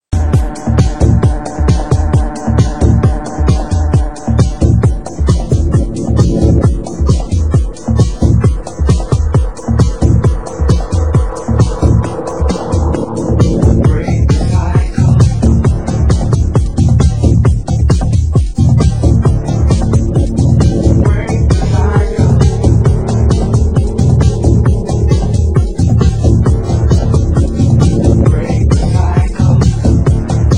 Genre: UK Techno